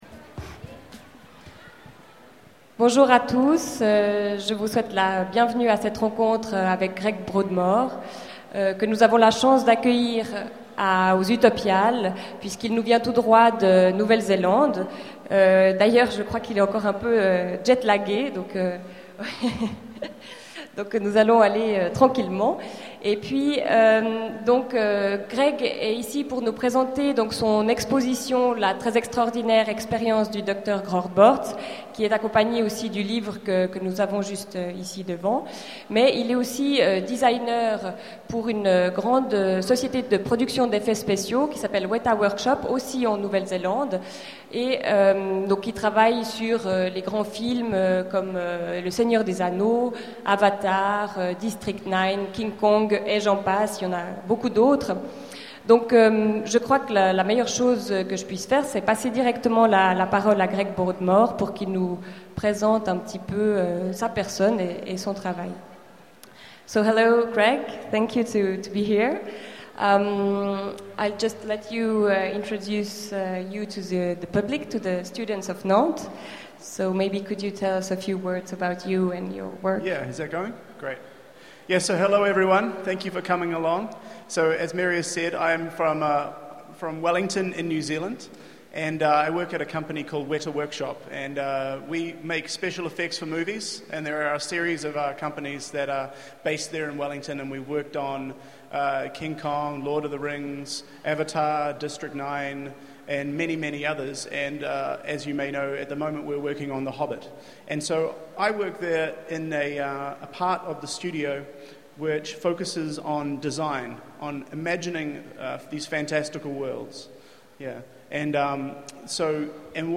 Utopiales 2011 : Conférence avec Greg Broadmore (VO)
Voici l'enregistrement de la première rencontre avec Greg Broadmore.